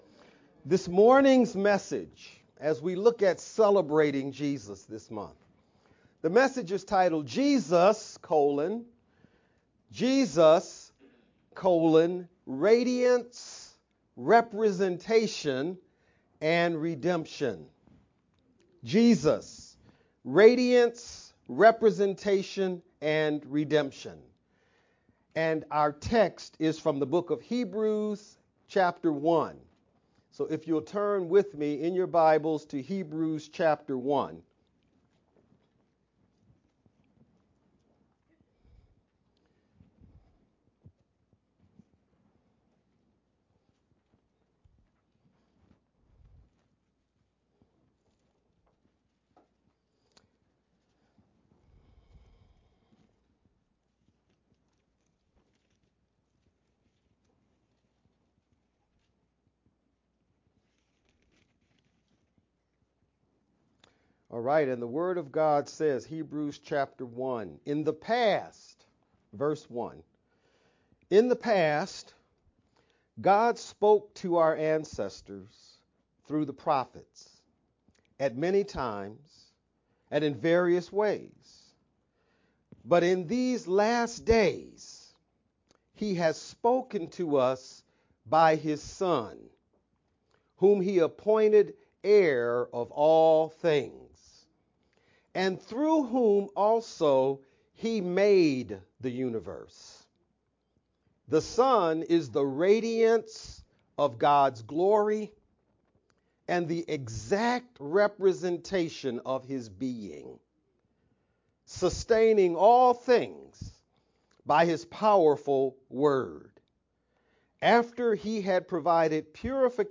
April-21st-Sermon-only_Converted-CD.mp3